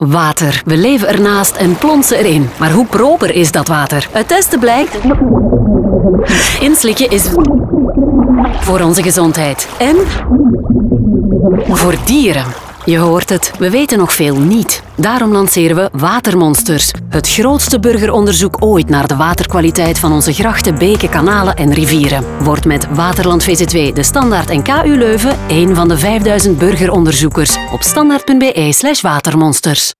Blublblmmbl bllmblub
Maar de belangrijkste stukken tekst, alles wat je dus écht wil weten voor je in dat water springt, blijft helaas nog onder water.
Sound & SFX: Raygun
DeStandaard_Watermonsters_Radio_NL30s.mp3